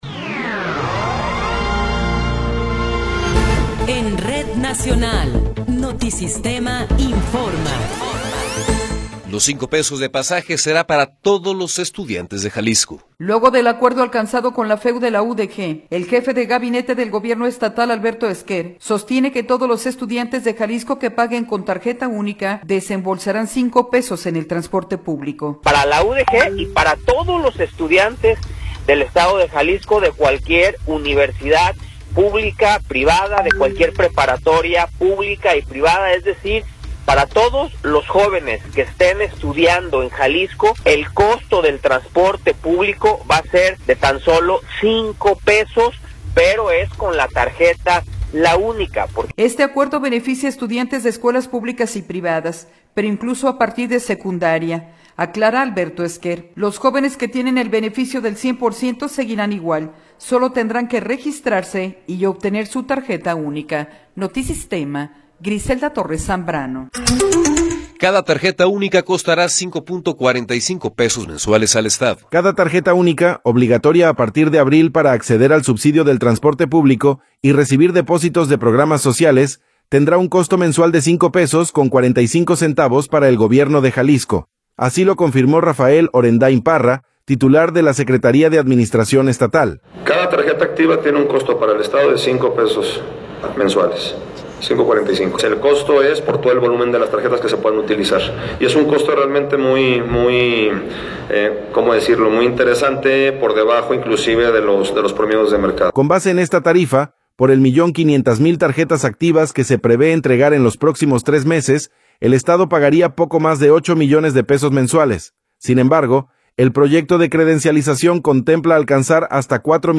Noticiero 11 hrs. – 12 de Enero de 2026
Resumen informativo Notisistema, la mejor y más completa información cada hora en la hora.